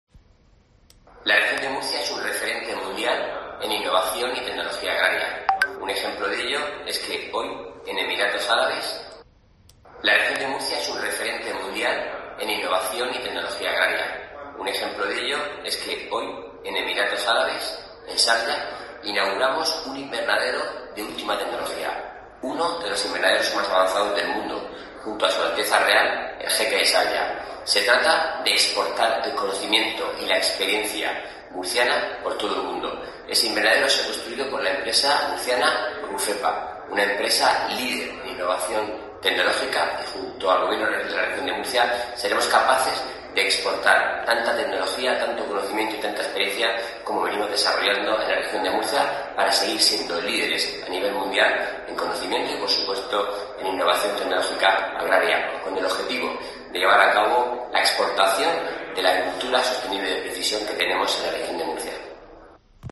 Antonio Luengo, consejero de Agua, Agricultura, Ganadería, Pesca y Medio Ambiente